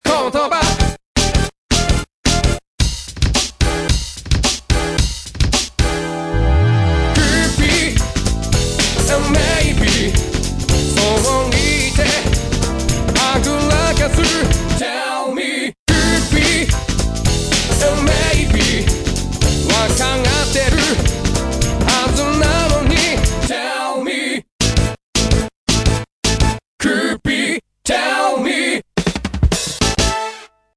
Remix Version
スクラッチを入れる関係上、どうしてもメロと一緒にコーラスを
「Tell me」のコーラスも素材として声だけのものを作って